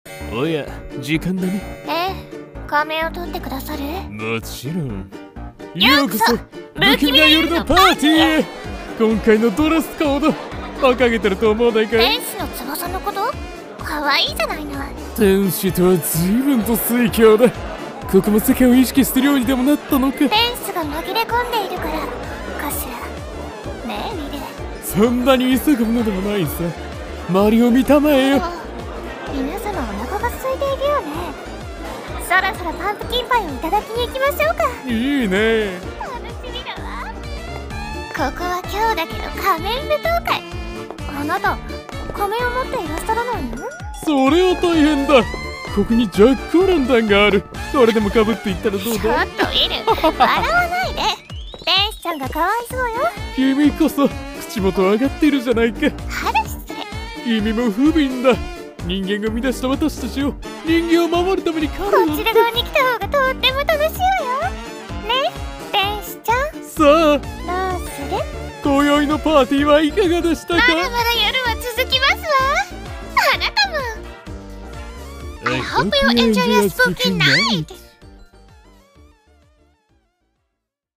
二人声劇「Spooky night!!」